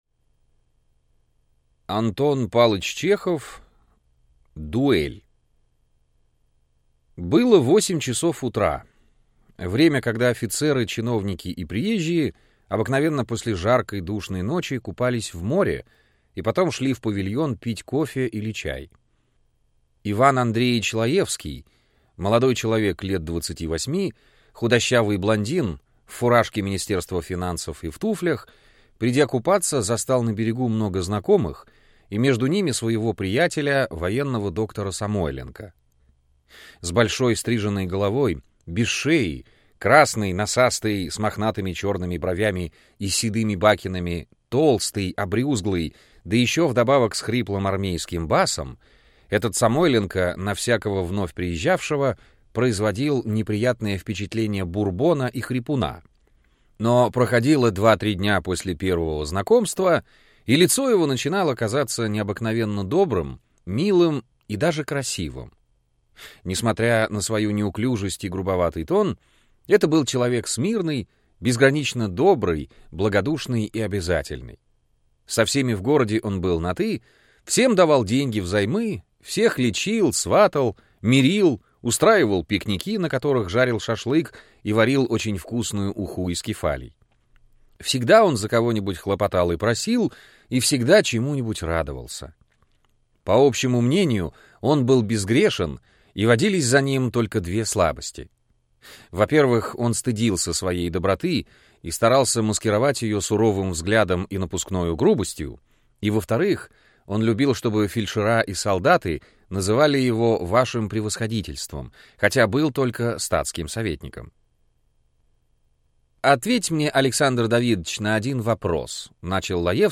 Аудиокнига Дуэль | Библиотека аудиокниг